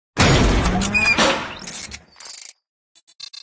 machine_intro_01.ogg